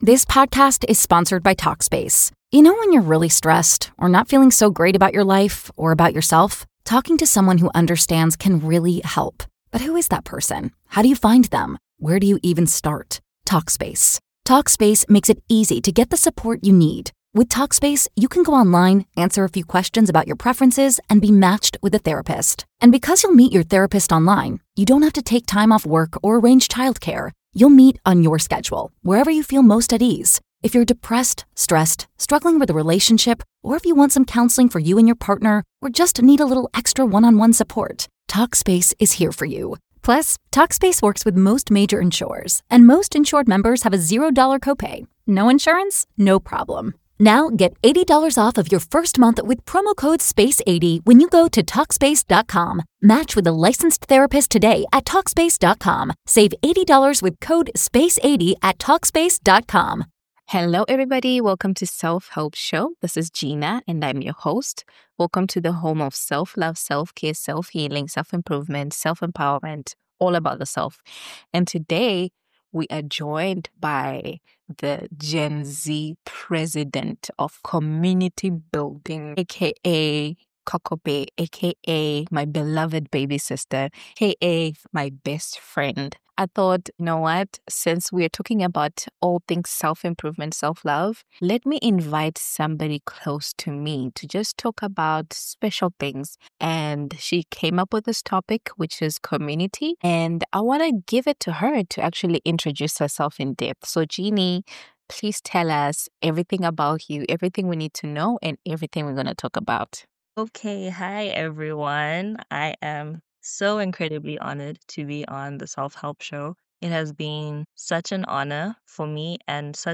1 Finding Her: Interview